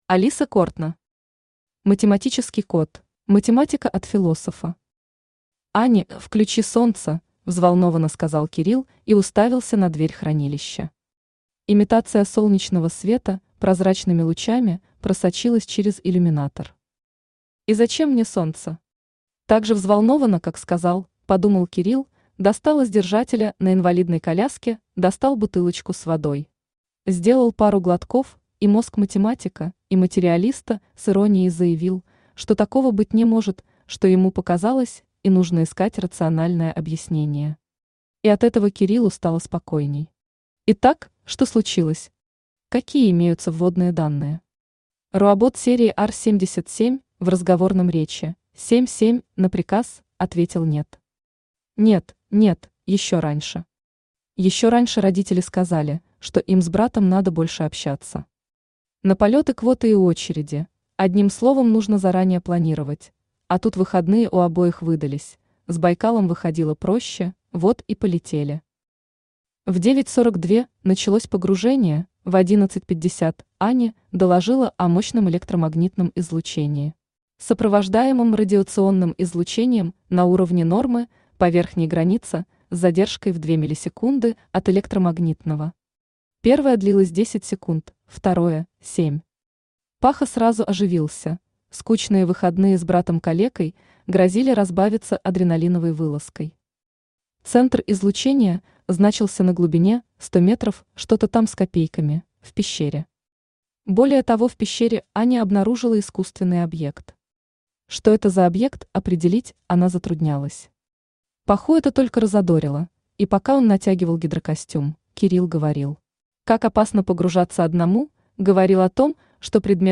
Аудиокнига Математический кот | Библиотека аудиокниг
Aудиокнига Математический кот Автор Алиса Кортно Читает аудиокнигу Авточтец ЛитРес.